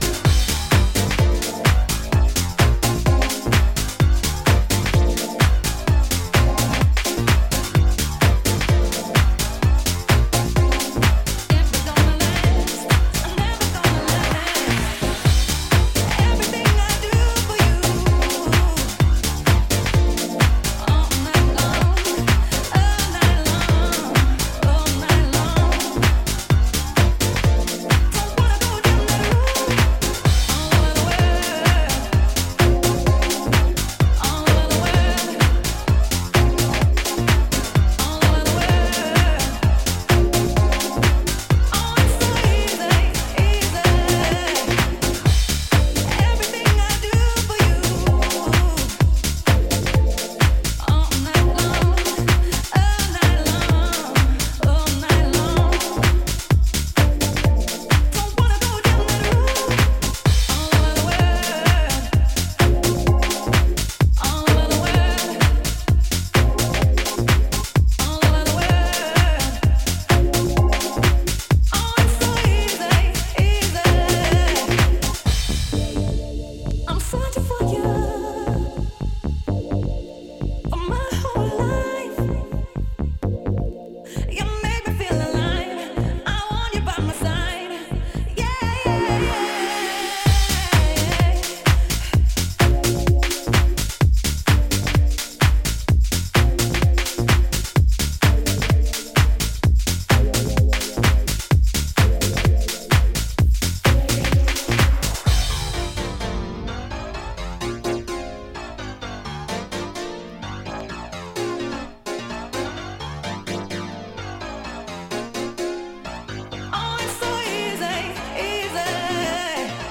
vocal mix